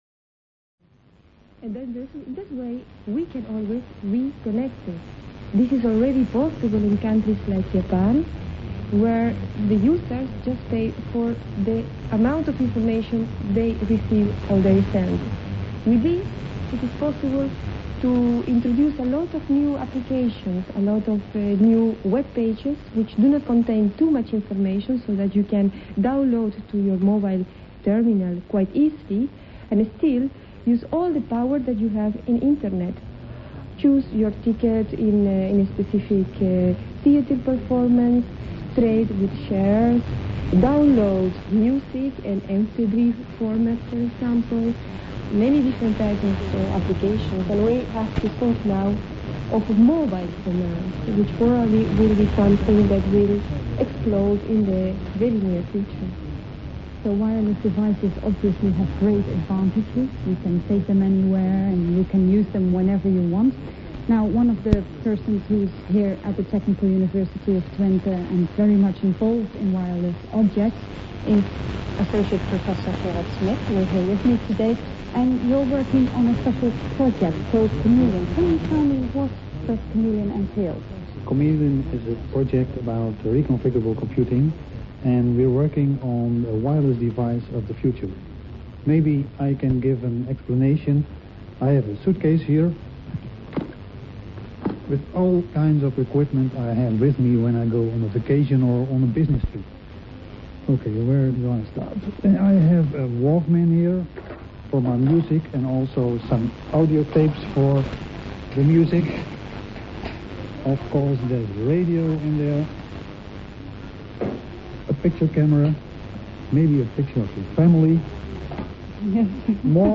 Radio Interview July 2000
The subject was: "Communication and chips". The quality of the recording is poor.